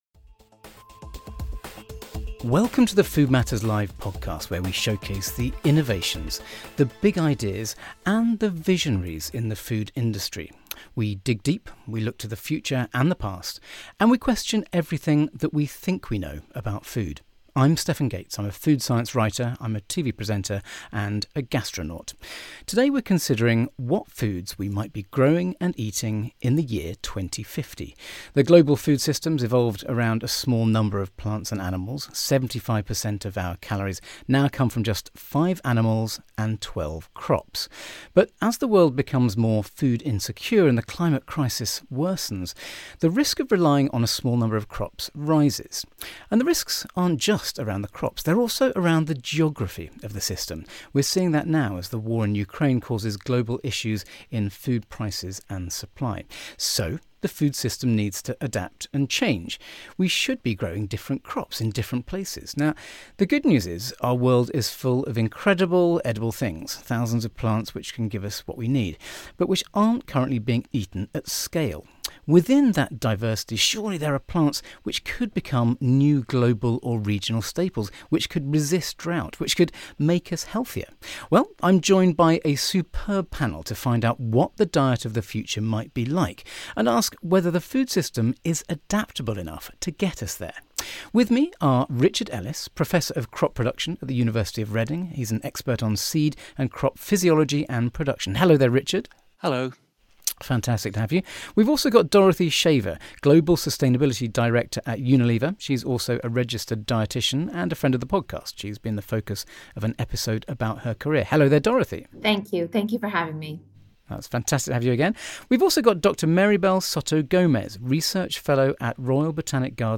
In this episode of the Food Matters Live podcast, our panel of experts look at what the diet of the future might be like and ask whether the food system is adaptable enough to get us there.